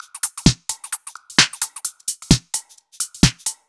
Index of /musicradar/uk-garage-samples/130bpm Lines n Loops/Beats
GA_BeatnPercE130-10.wav